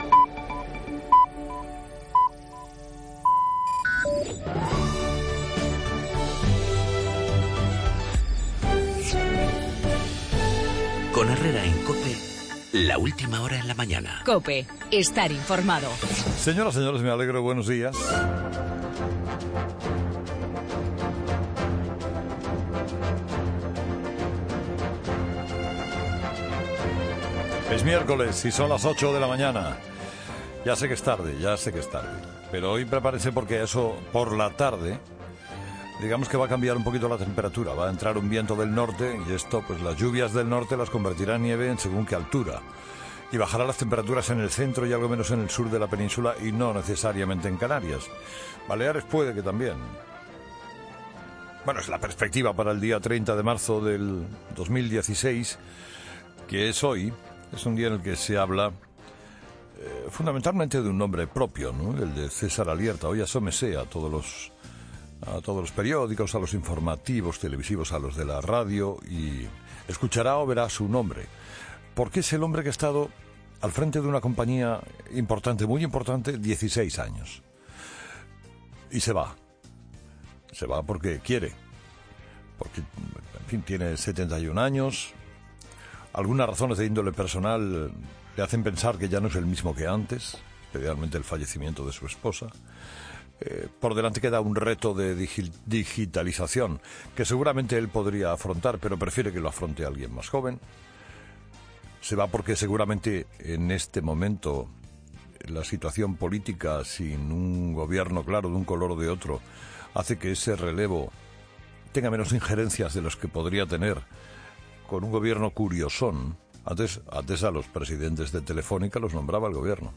Todo en el editorial de Carlos Herrera a las 8 de la mañana.